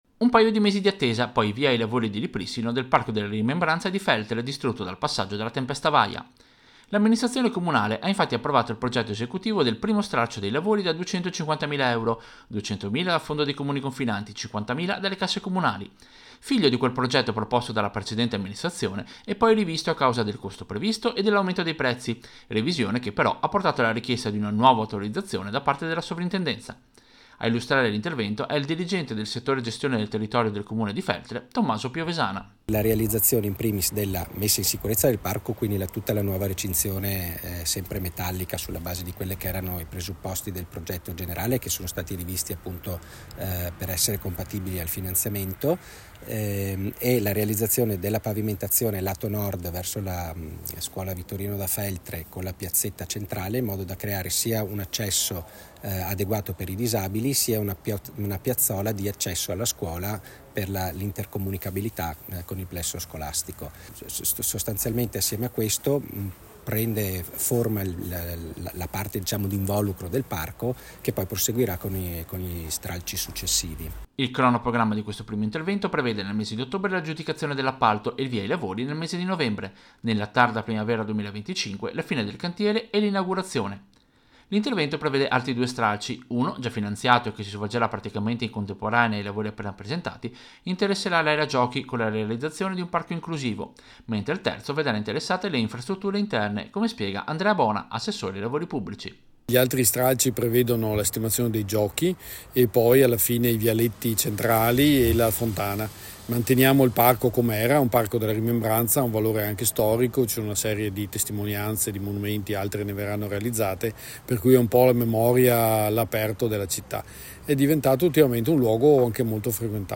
Servizio-Lavori-Parco-Rimembranza-Feltre.mp3